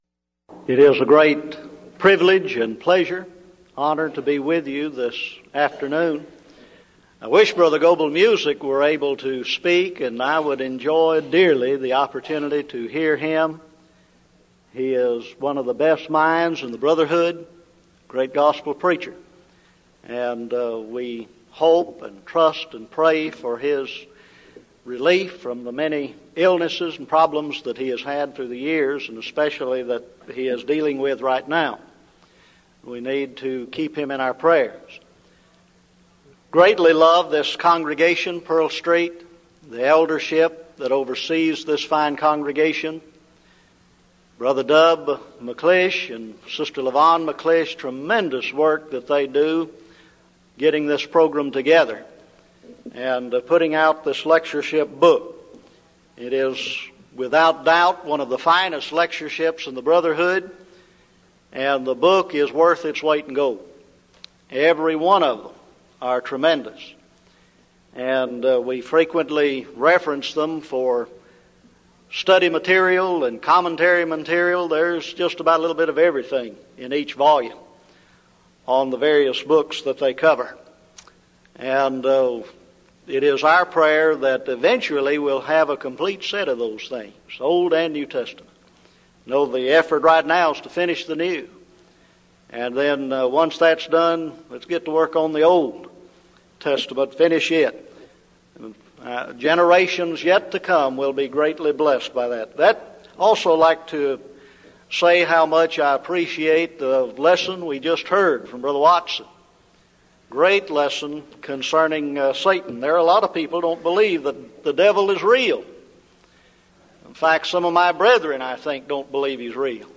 Event: 1998 Denton Lectures Theme/Title: Studies in the Books of I, II Peter and Jude